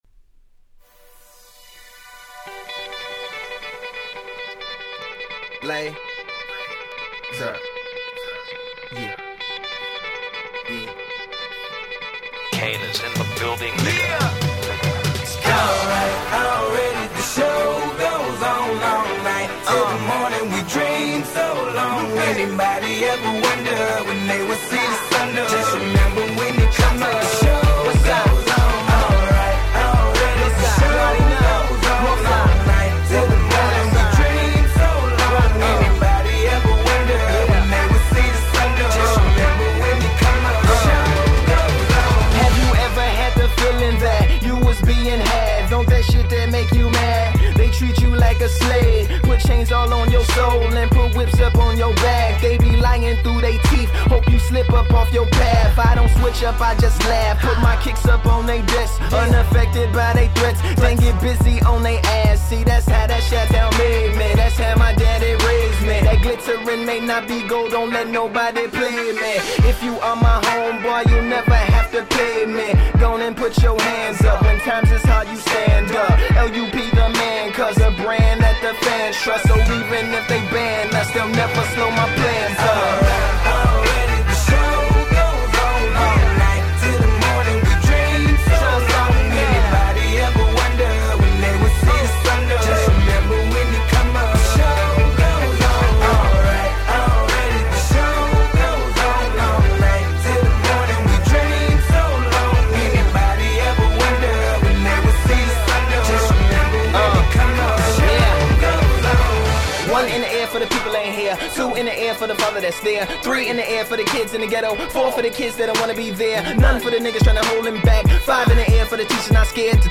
11' Super Hit Hip Hop !!
説明不要の感動系Hip Hopの超名曲です！